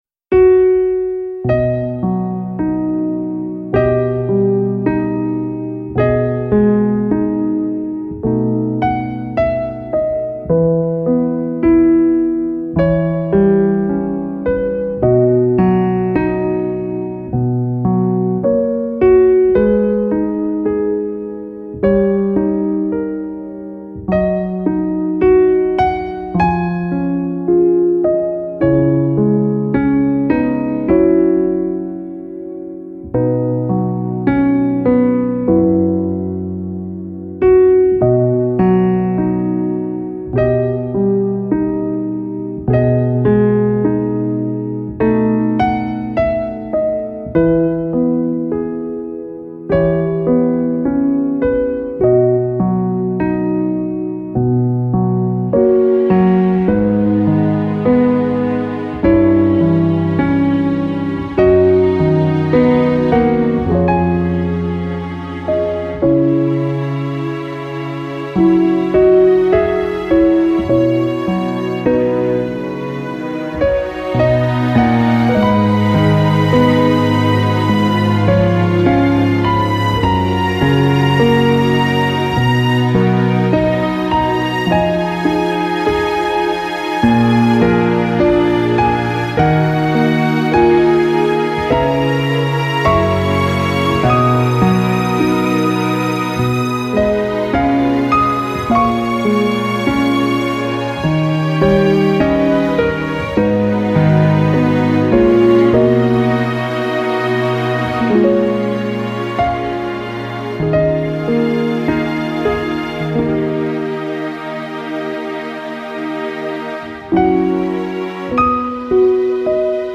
电视原声带+电视配乐 为戏量身打造 双CD